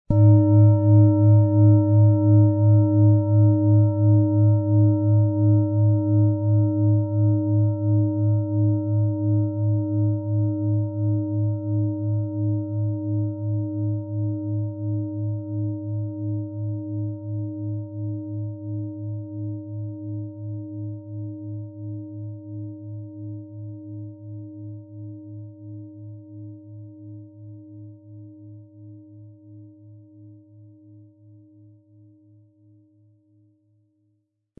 Nach uralter Tradition von Hand getriebene Planetenklangschale Eros.
• Tiefster Ton: Mond
• Höchster Ton: Saturn
Durch die traditionsreiche Herstellung hat die Schale stattdessen diesen einmaligen Ton und das besondere, bewegende Schwingen der traditionellen Handarbeit.
PlanetentöneEros & Mond & Saturn (Höchster Ton)
MaterialBronze